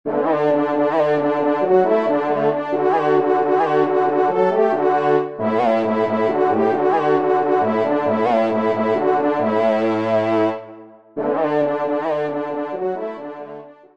Genre : Musique Religieuse pour  Quatre Trompes ou Cors
Pupitre 4° Trompe